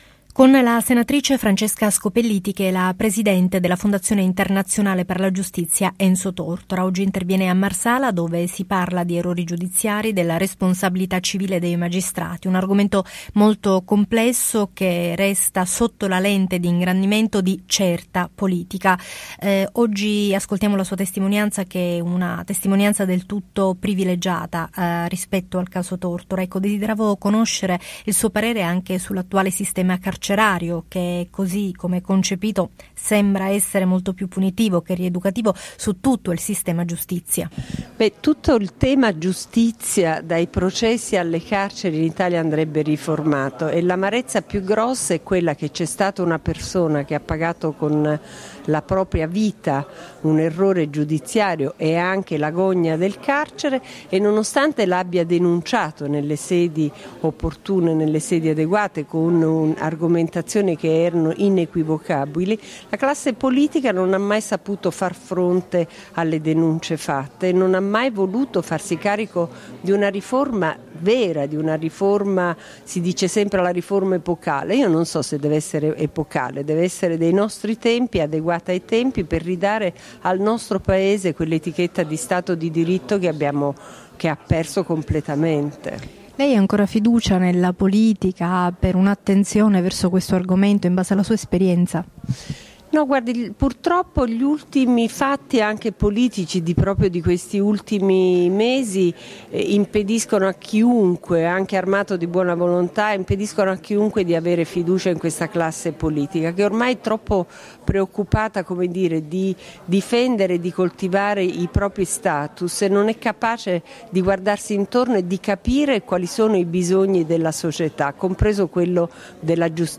presso l'Ente Mostra di Pittura, ex Convento del Carmine a Marsala il 19 aprile 2013, in occasione della Conferenza "Errori giudiziari e responsabilità civile dei magistrati".
Intervista trasmessa in audio su RMC101 e in video su Telesud.